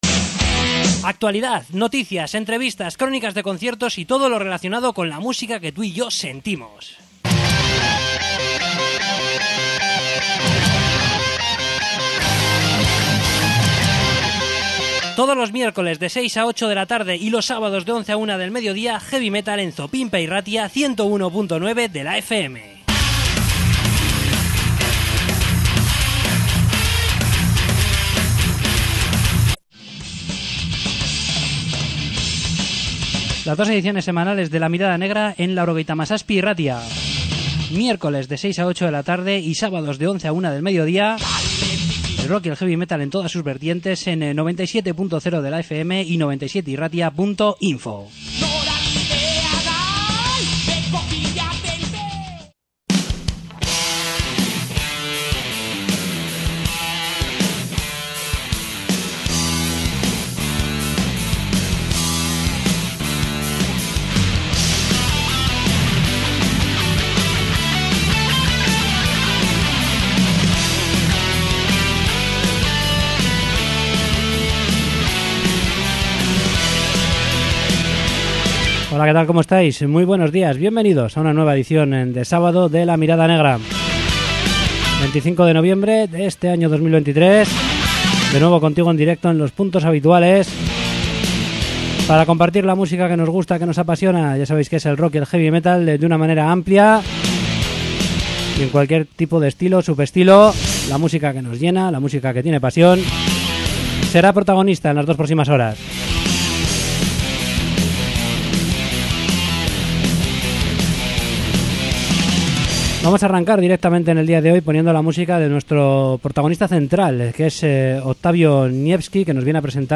Entrevista
Acústico en directo